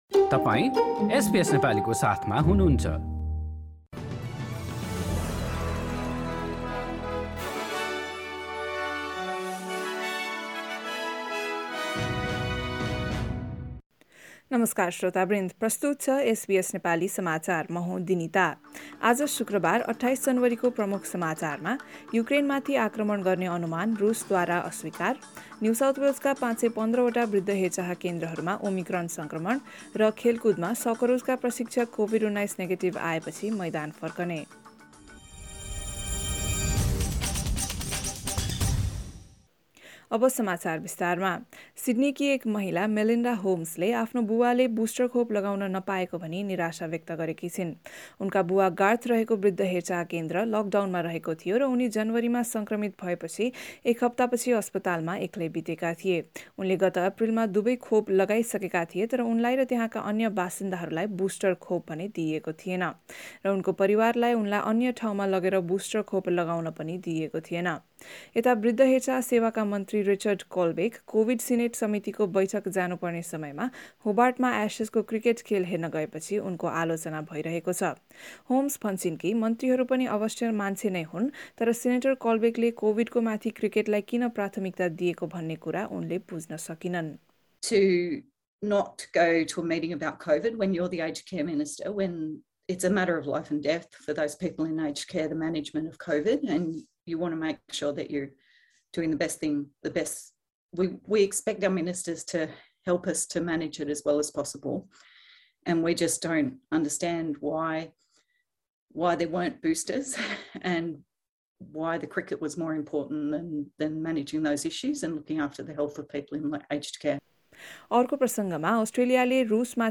एसबीएस नेपाली अस्ट्रेलिया समाचार: शुक्रबार २८ जनवरी २०२२